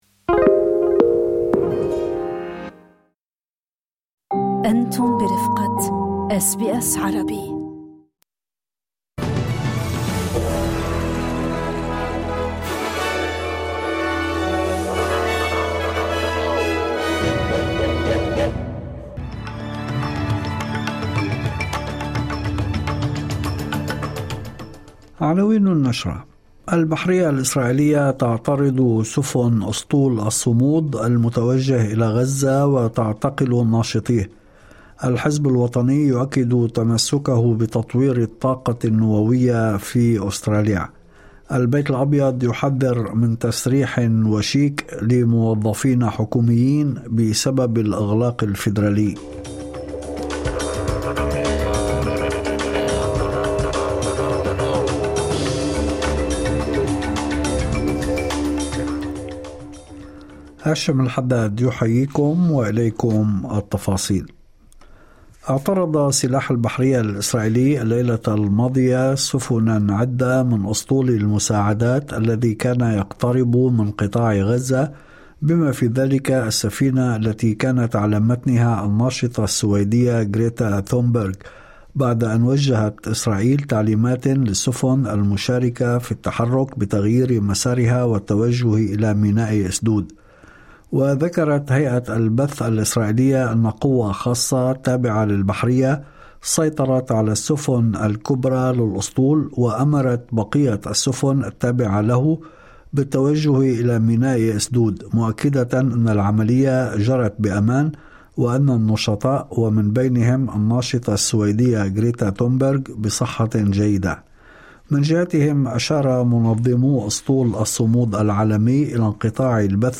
نشرة أخبار المساء 02/10/2025